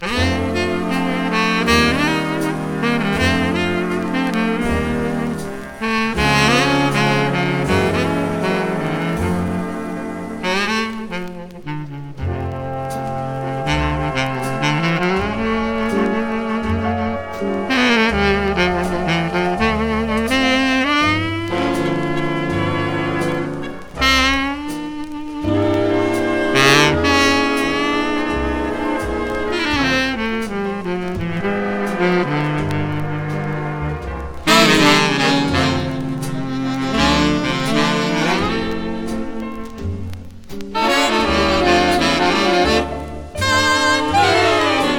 躍動感溢れる演奏、艶やかでふくよかなトーンと聴きどころ満載の良盤です。
Jazz　USA　12inchレコード　33rpm　Mono